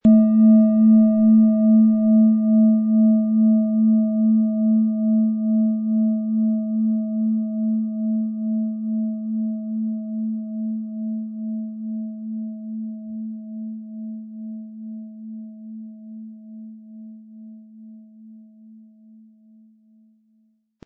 Planetenschale® Fühle Dich wohl & Innere Tiefe fühlen mit Biorhythmus Seele & Alphawellen, Ø 13,9 cm, 260-320 Gramm inkl. Klöppel
• Mittlerer Ton: Alphawelle
Viel Freude haben Sie mit einer Biorhythmus Seele, wenn Sie sie sanft mit dem kostenlosen Klöppel anspielen.